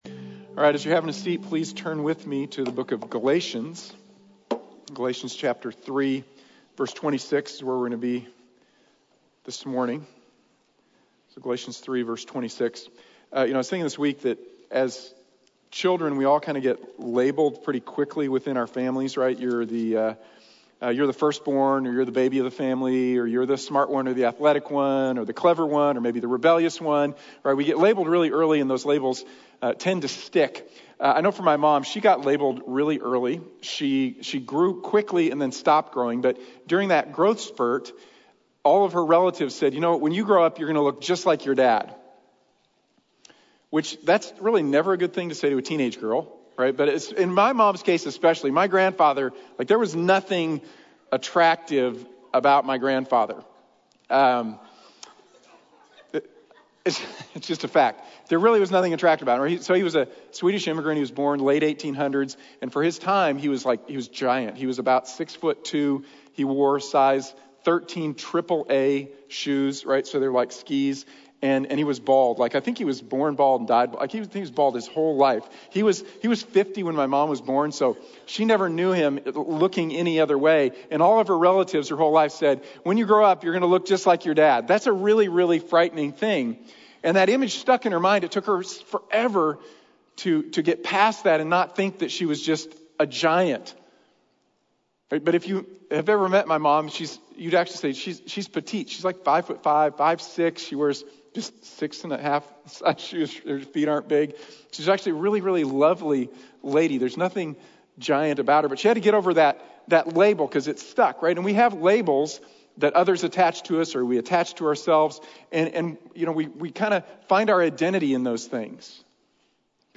Children of God | Sermon | Grace Bible Church